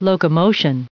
1660_locomotion.ogg